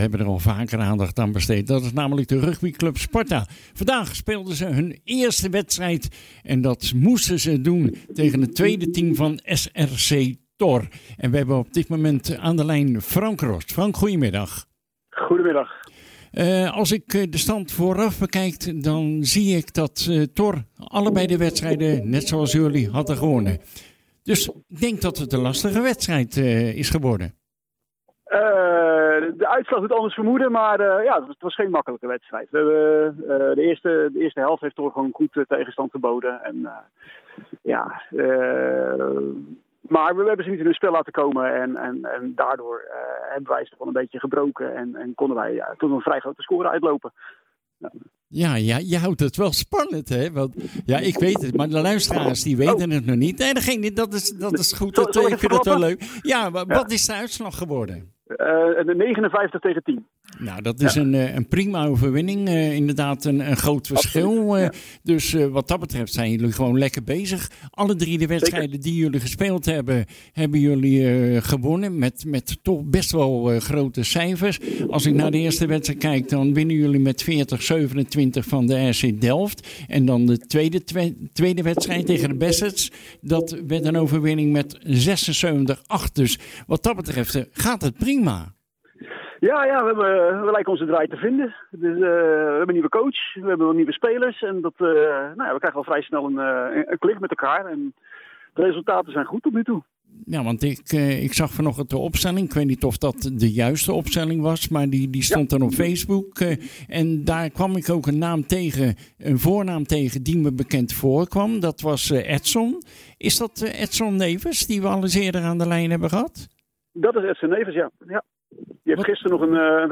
Voor Sparta Rugby stond de derde wedstrijd op het programma, een thuiswedstrijd tegen SRC Thor. Uiteindelijk wonnen de Capellenaren met 59-10. Na afloop spraken wij met een van de spelers